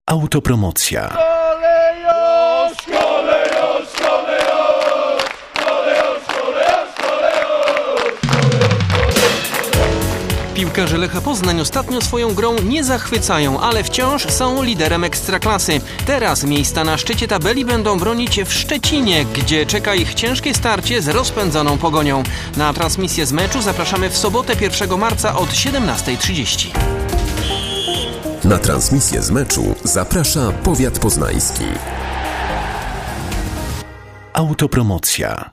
Radiowe zapowiedzi meczów